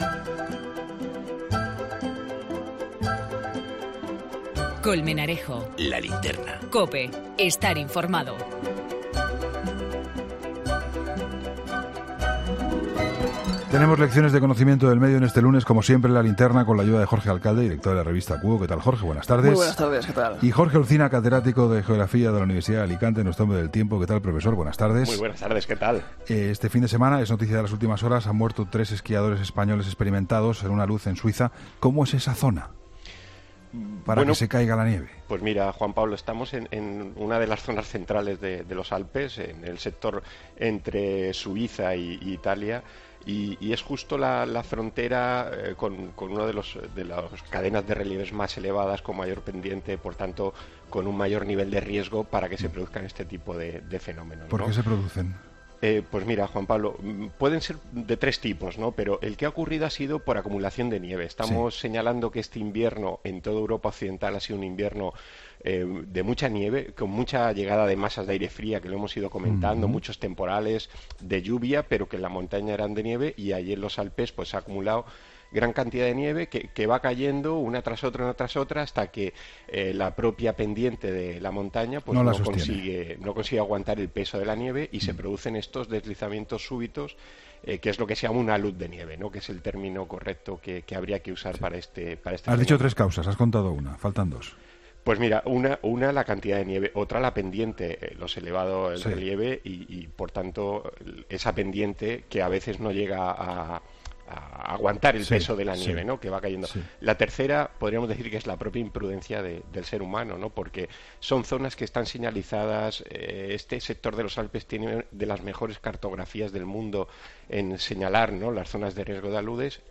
La sección de Conocimiento del medio de ‘La Linterna’ ha analizado esta semana las causas por las que se produce un alud en la alta montaña, como el que acabó con la vida este fin de semana de tres españoles en el cantón de Valias, en los Alpes suizos.